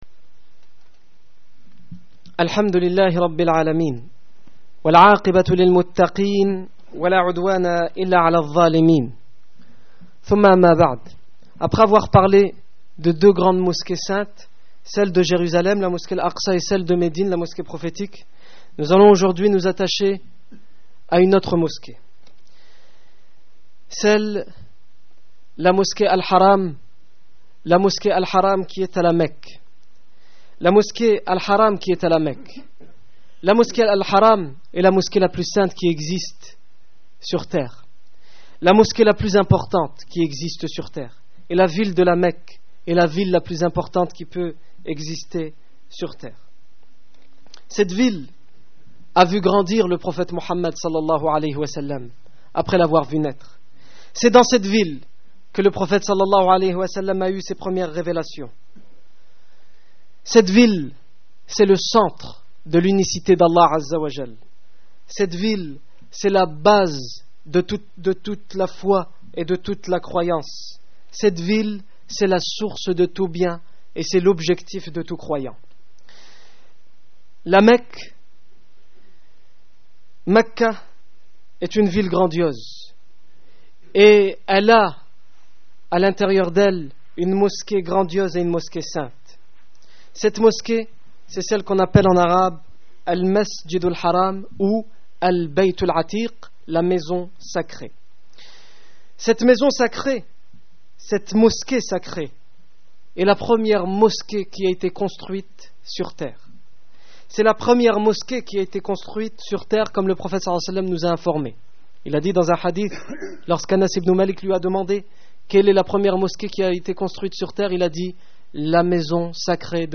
Discours du 30 octobre 2009
Accueil Discours du vendredi Discours du 30 octobre 2009 L'histoire de masjid AlHaram à la Mecque